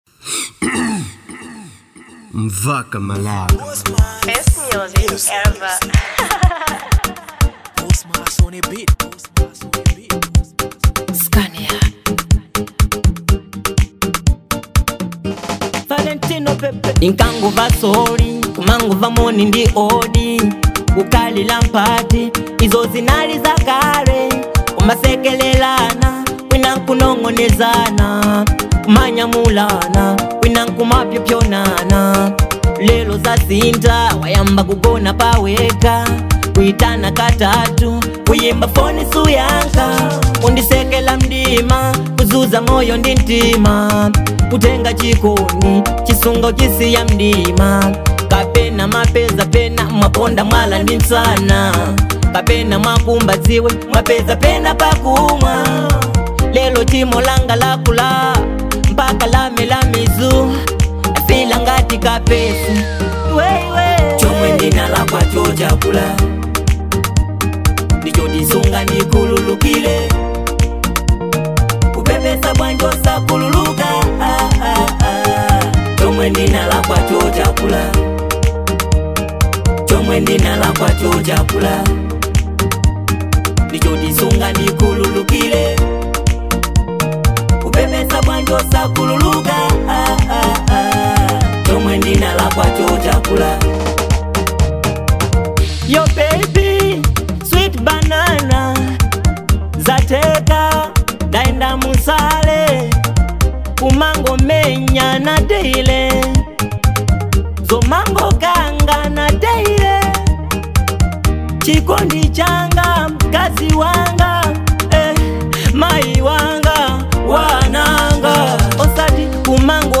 type: love song